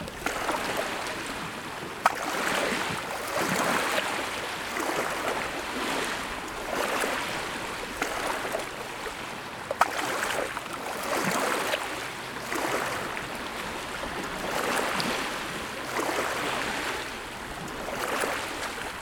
Tune_SSB
Communications HAM Radio Short-Wave signal SSB Tunning sound effect free sound royalty free Nature